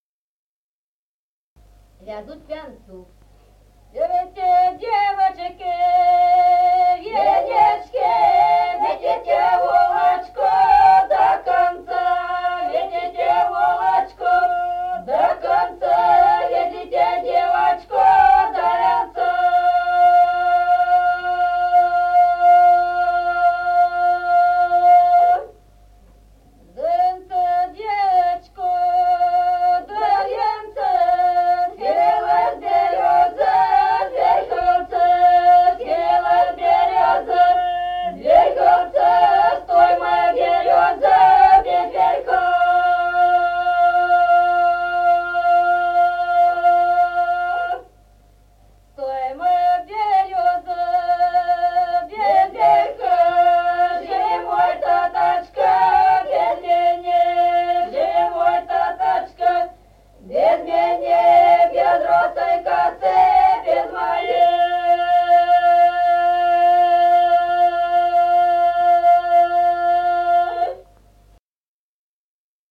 Народные песни Стародубского района «Берите, девочки», свадебная, «вязуть к вянцу».
с. Остроглядово.